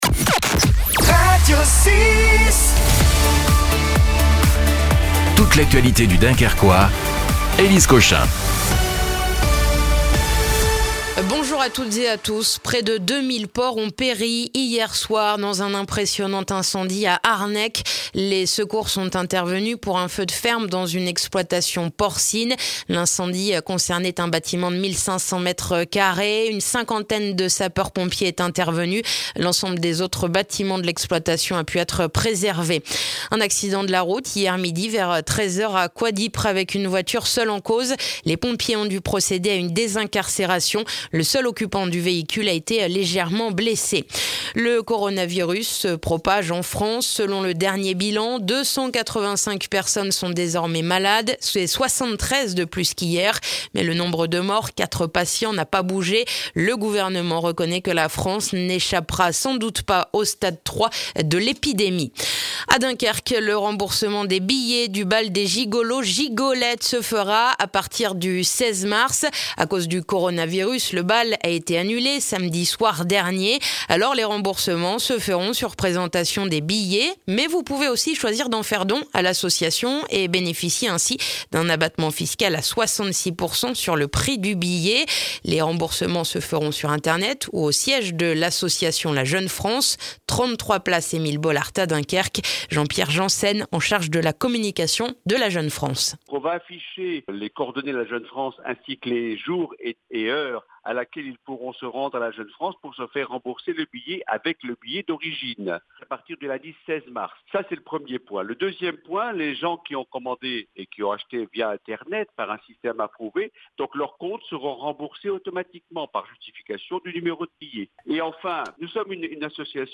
Le journal du jeudi 5 mars dans le dunkerquois